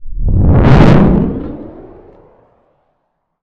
sci-fi_vehicle_thrusters_pass_01.wav